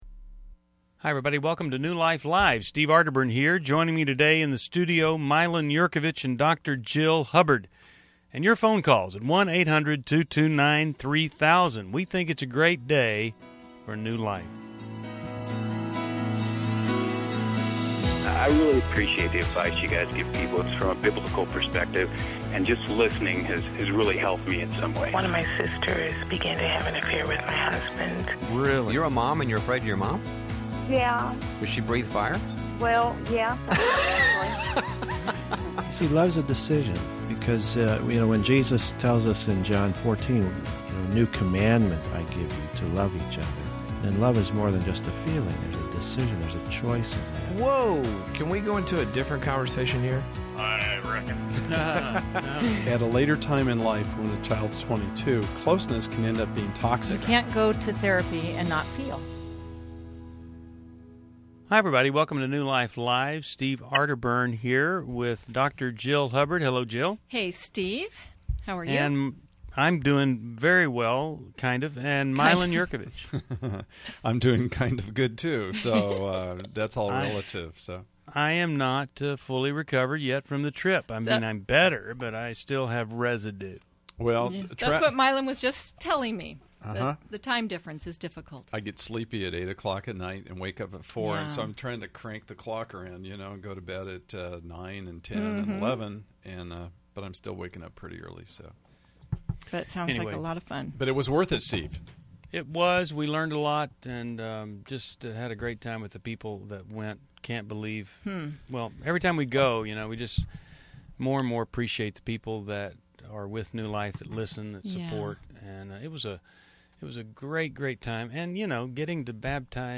Discover solutions to marriage, anger, and trust issues on New Life Live: June 22, 2011, as experts address real caller struggles with relationships.